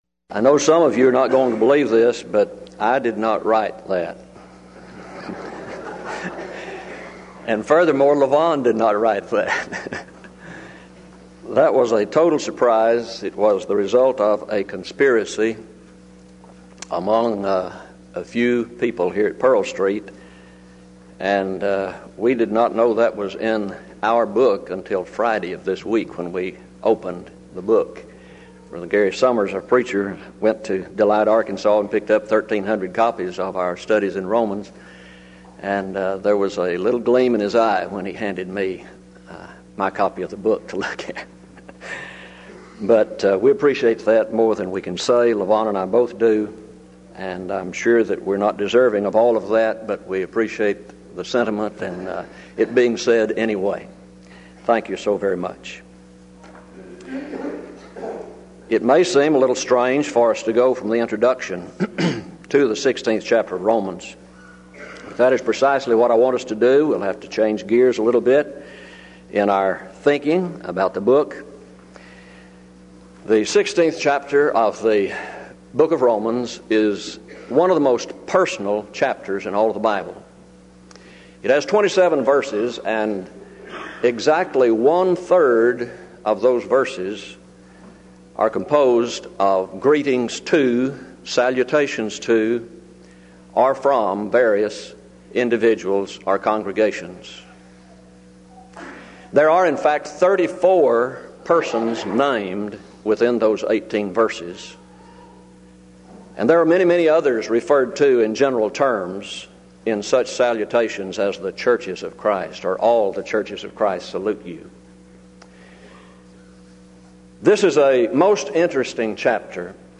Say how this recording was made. Event: 1996 Denton Lectures Theme/Title: Studies In The Book Of Romans